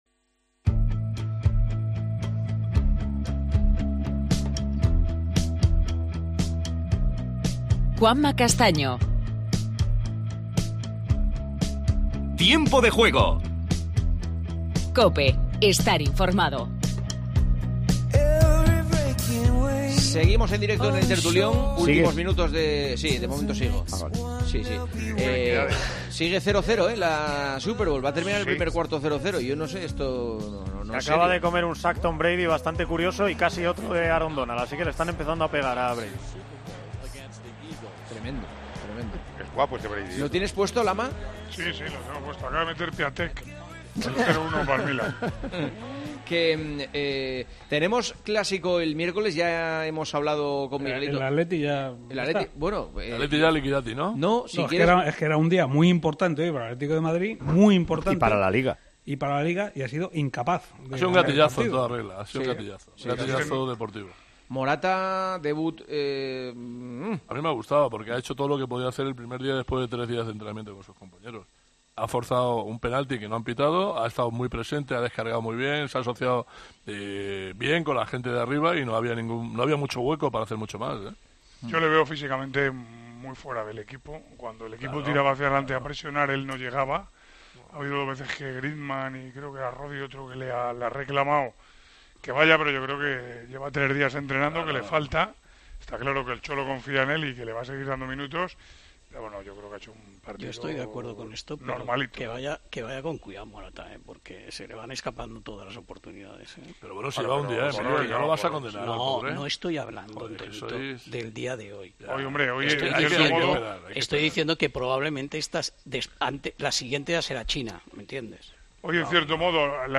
AUDIO: Continuamos el tertulión: la derrota del Atleti.